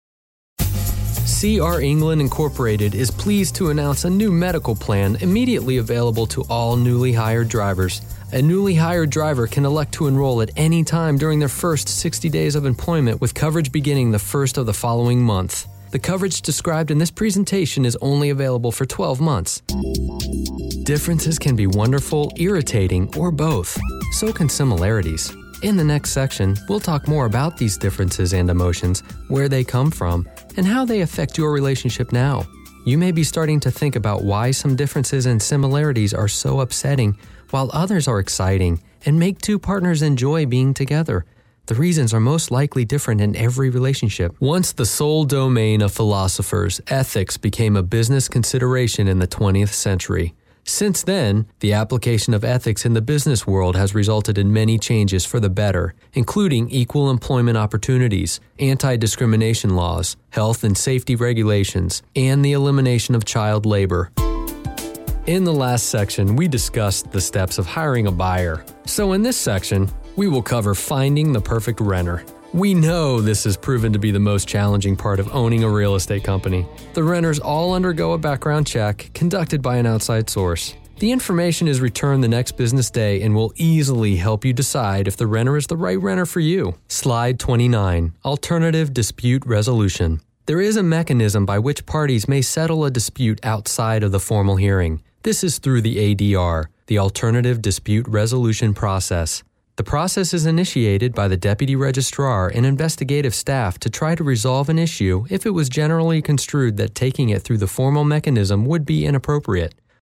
Narration
English - USA and Canada
Middle Aged